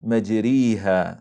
eller yā' (ي)